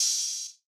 YM Open Hat 5.wav